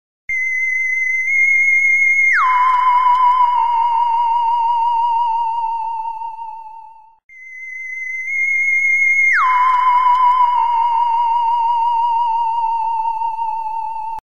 Download Free Scifi Sound Effects
Download Scifi sound effect for free.
Scifi